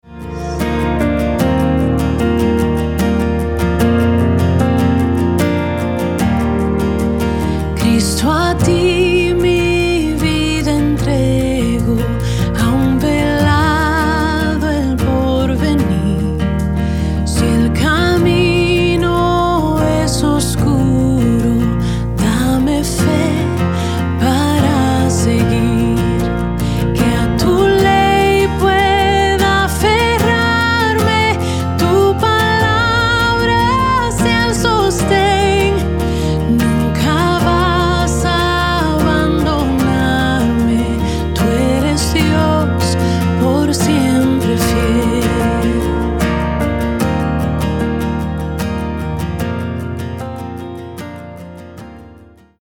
Acordes - Bb-C